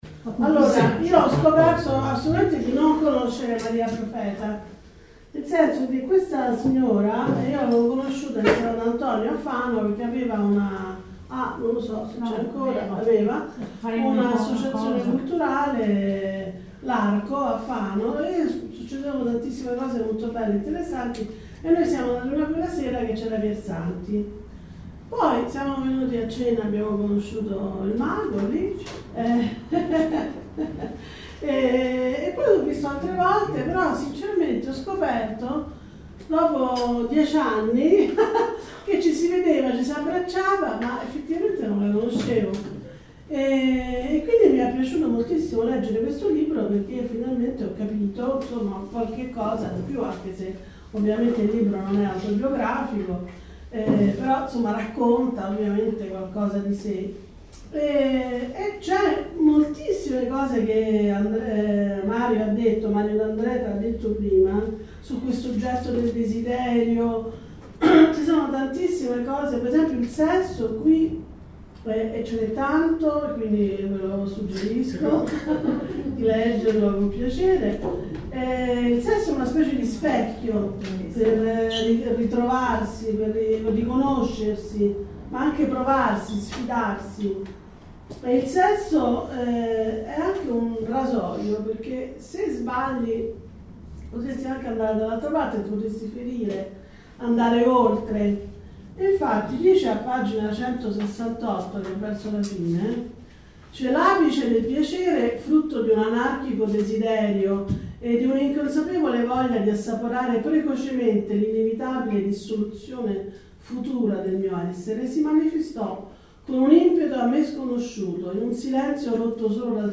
Qui di seguito la presentazione del libro tenuta nell’ambito dell’edizione 2018 del Land Art al Furlo dal tema NERO